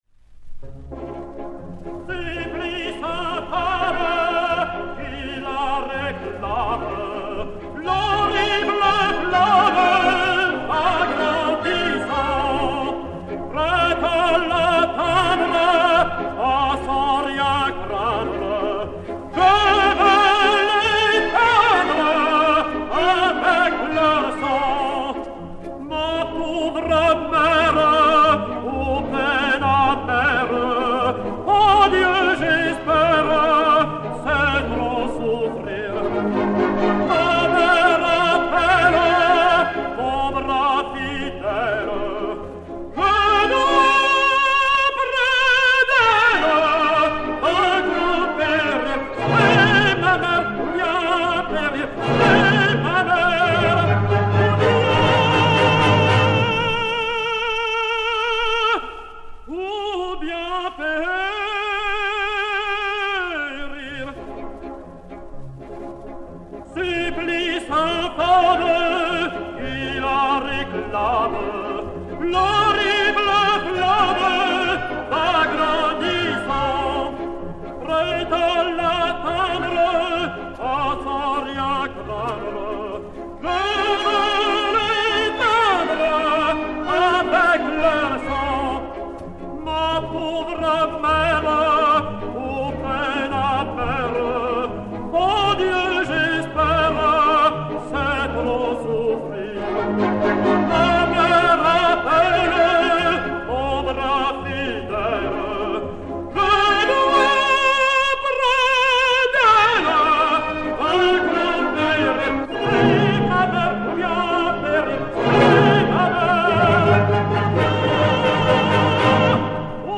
ténor français